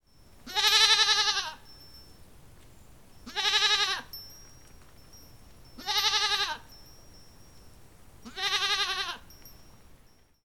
goat
Category 🐾 Animals